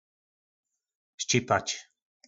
Slovník nářečí Po našimu
Štípat - Ščipač